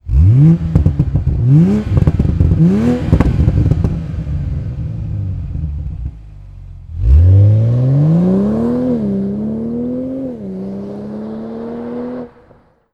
• Silencieux arrière à valves
Un son profond et métallique caractéristique des faibles régimes moteur tandis que c'est une suite de plaisirs auditifs jusqu'au rupteur.
En valves ouvertes la sonorité de votre moteur s'exprime pleinement, en revanche quand les valves de votre Toyota GR Supra FAP/OPF (03/2019+) sont fermées le son est modéré pour une conduite plus discrète et un confort amélioré.
Toyota_GR_Supra_REMUS_Axle-Back.mp3